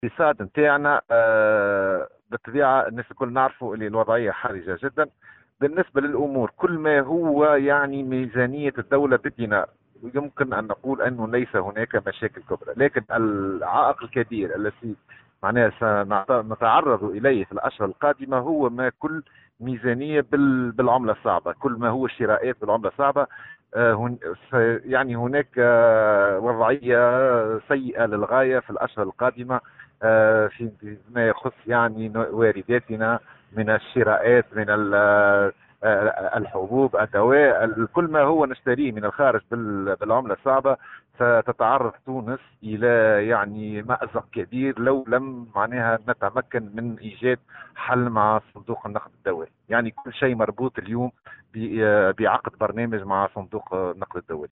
خبير في الاقتصاد والأسواق المالية: تونس ستواجه مأزقا خلال الأشهر القادمة